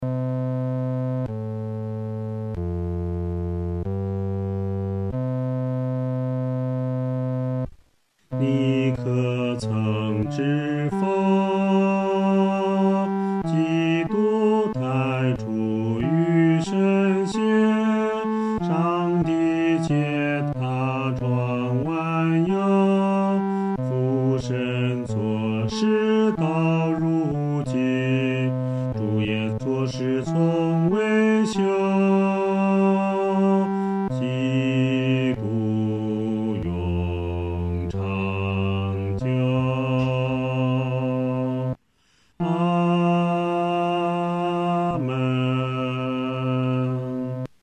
合唱
男低